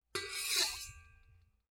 Metal_60.wav